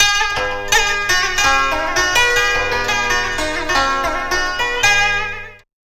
CHINAZITH1-L.wav